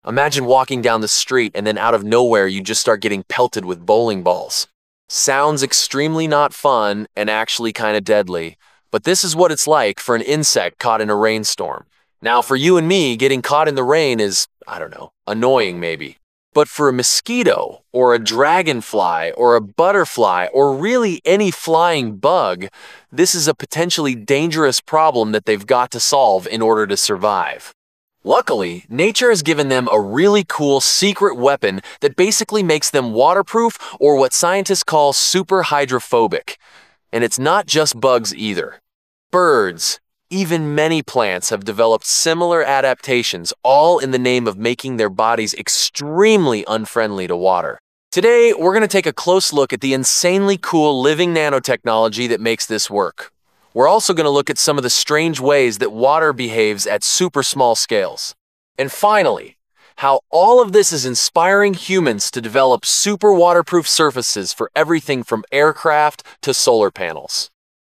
Explanatory Broad Emotional.wav